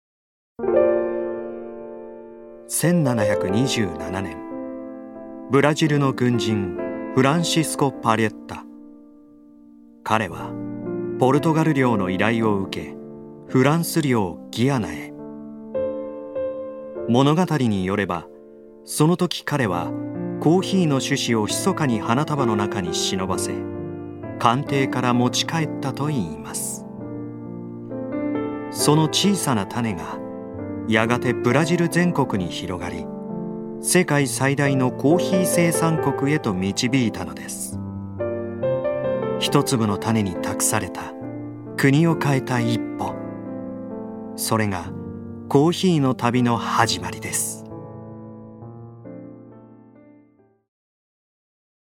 所属：男性タレント
ナレーション５